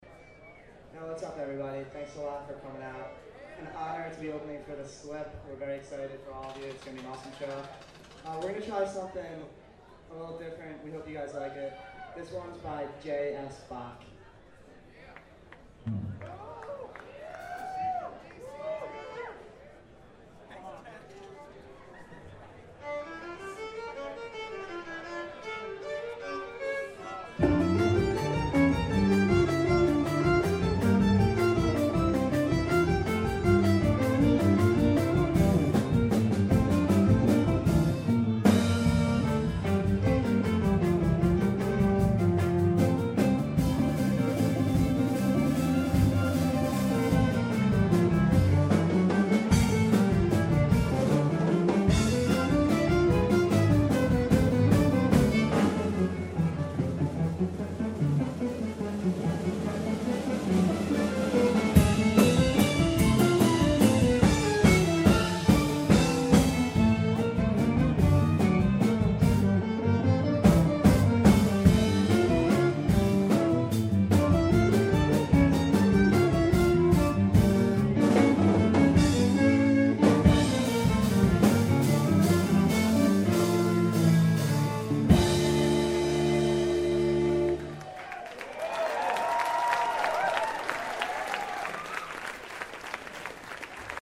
Paradise Rock Club, Boston